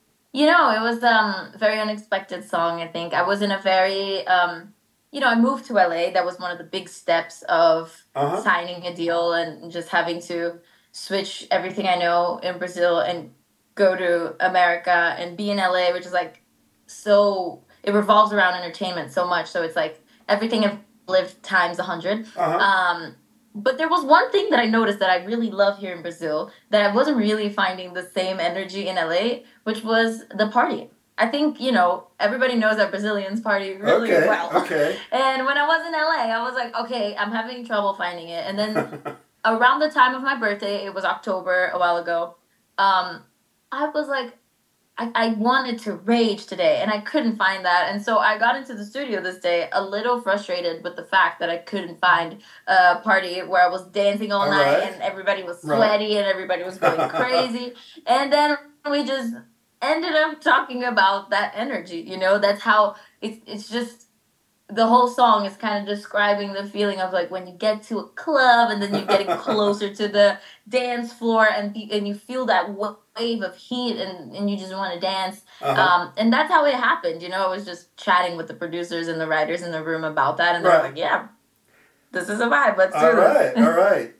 Interview with Brazilian pop star Any Gabrielly, about her new songs "Sweat" and "'Waste Your Love."
Any_Gabrielly_inteview_excerpt.mp3